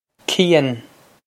Cian Kee-un
Pronunciation for how to say
This is an approximate phonetic pronunciation of the phrase.